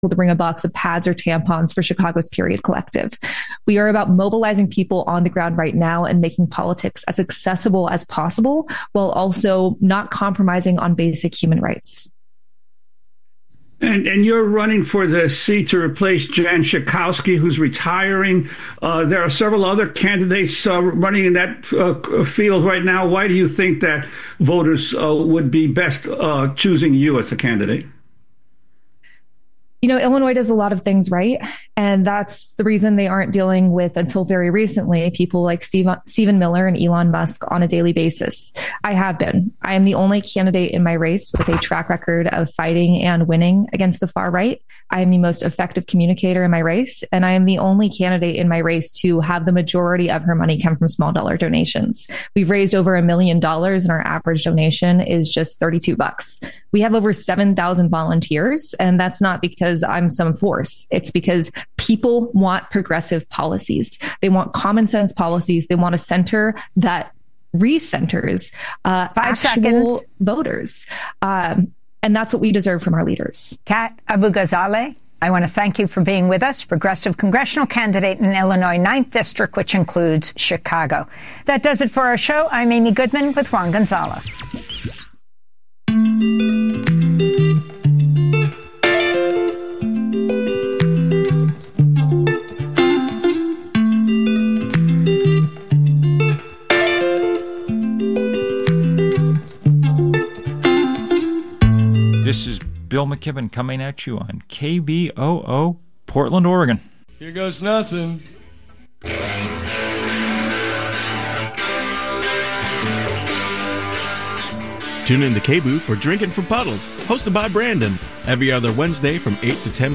Evening News on 09/23/25